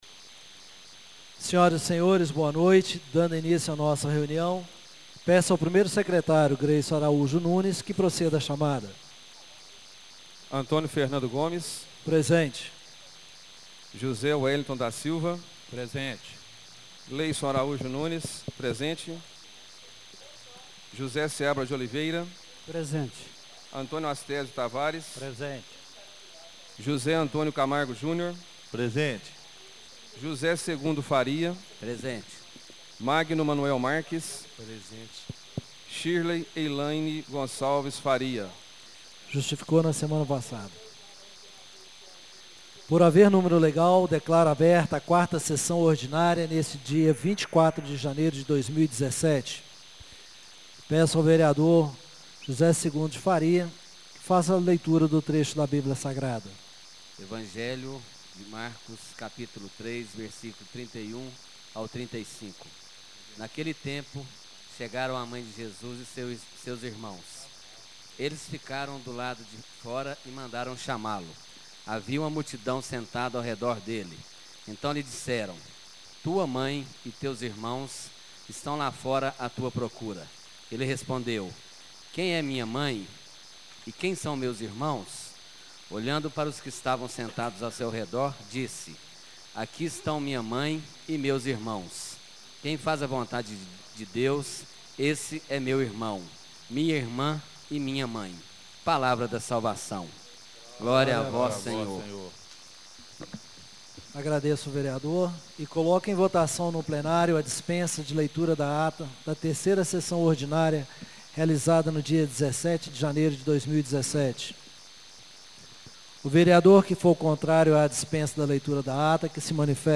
Por haver número legal, declaro aberta a 4ª Sessão Ordinária neste dia 24 de janeiro de 2017.